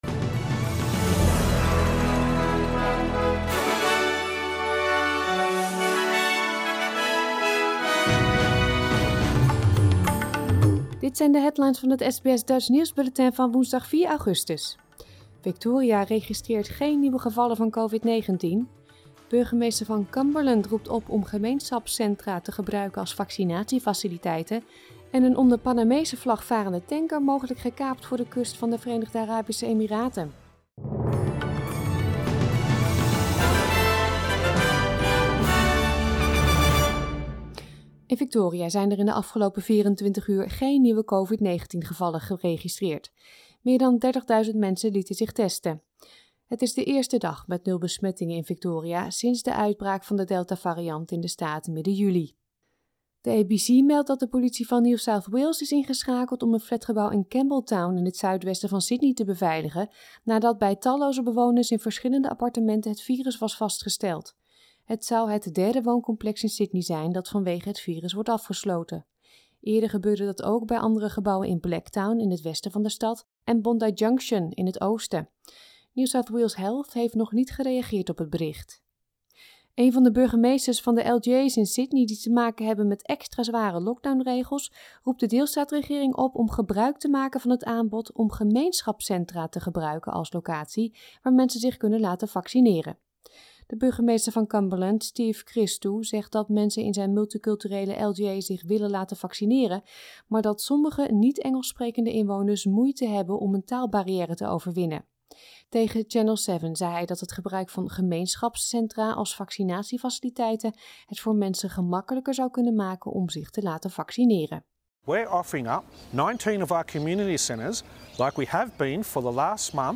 Nederlands/Australisch SBS Dutch nieuwsbulletin van woensdag 4 augustus 2021